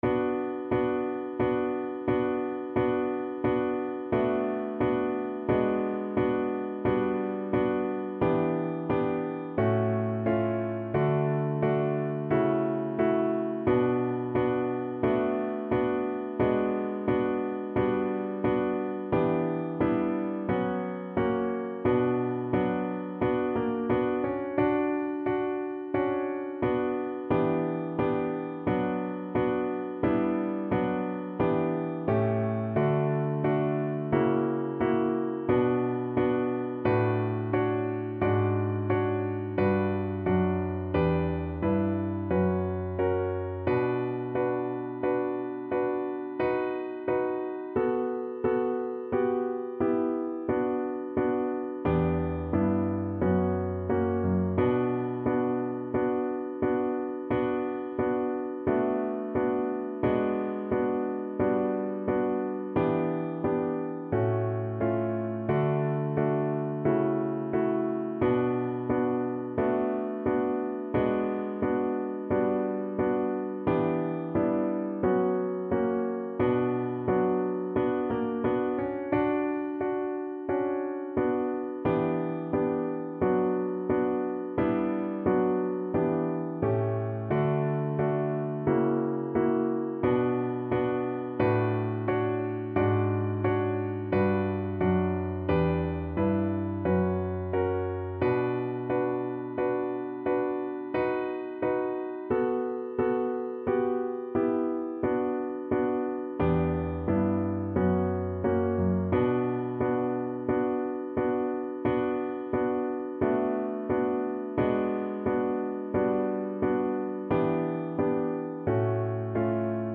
Traditional Music of unknown author.
Andante =c.88
kojo_no_tsuki_VOICE_kar1.mp3